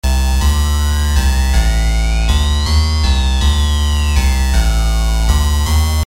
使用Fruity Loops 9中的Vanguard制作。
标签： 160 bpm Electronic Loops Bass Loops 1.01 MB wav Key : Unknown
声道立体声